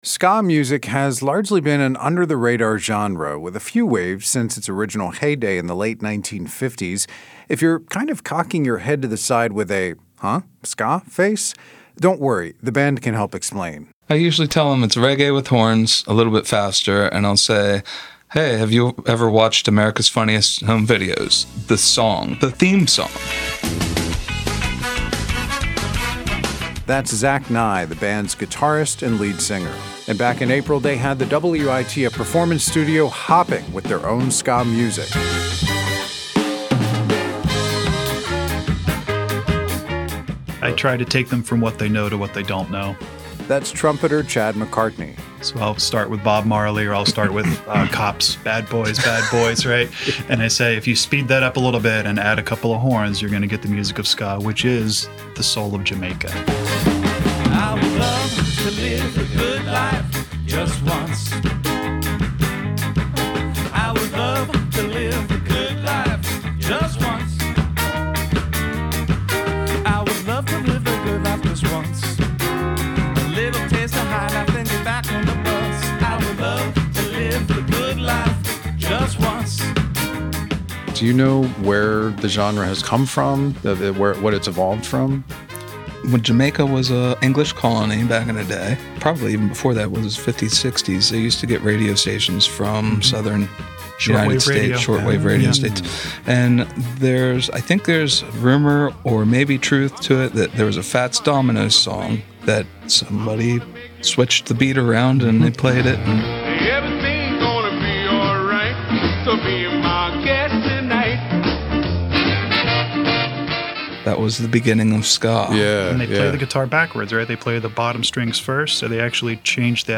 Ska band Fink's Constant performs for WITF Music on April 5, 2023.